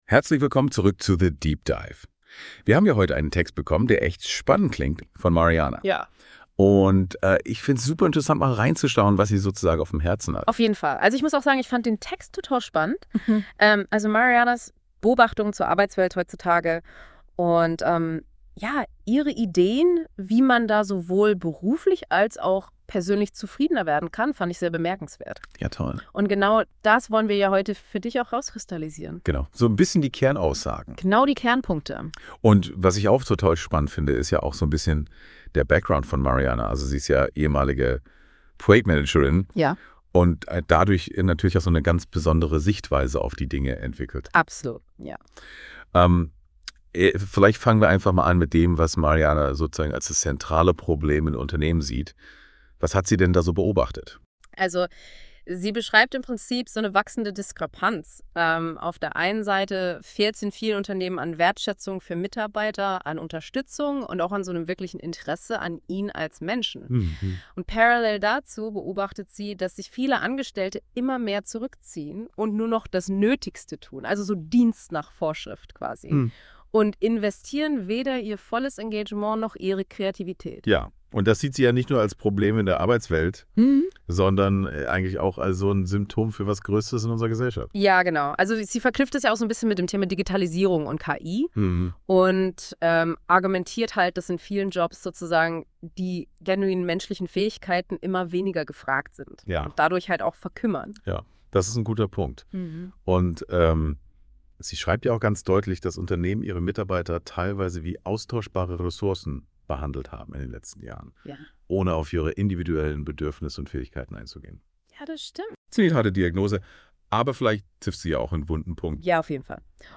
Erfahre in meinem KI-generierten Podcast mehr darüber, was ich mit Euch in der Welt verändern möchte: